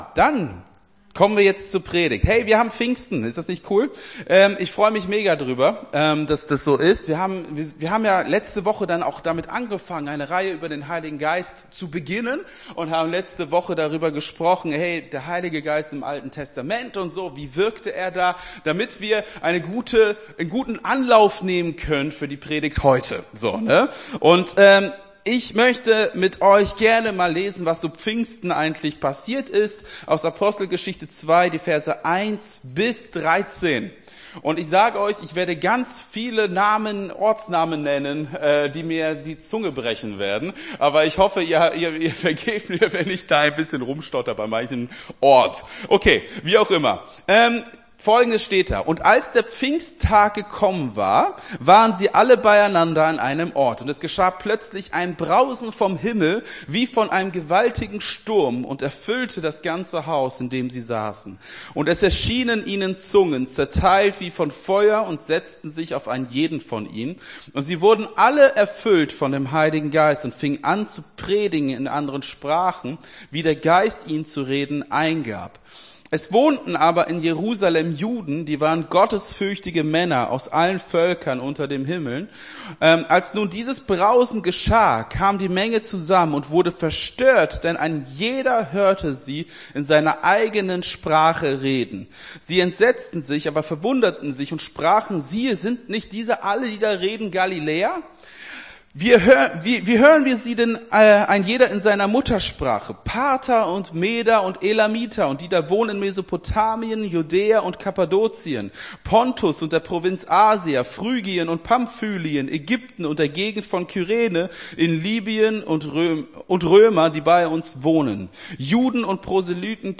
Gottesdienst 28.05.23 - FCG Hagen